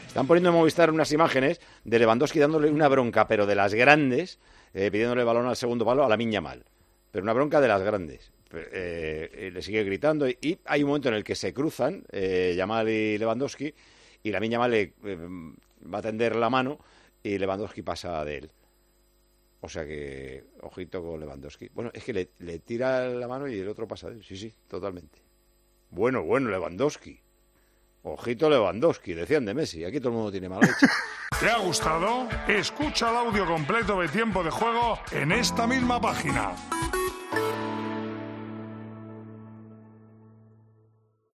En la previa del encuentro entre el Atlético de Madrid y Villarreal que comenzó a las 21:00h de este domingo en el Cívitas Metropolitano, el director y presentador de Tiempo de Juego, Paco González, analizó estas imágenes y recordó a lo que se decía sobre Leo Messi.